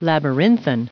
pronounciation
1878_labyrinthine.ogg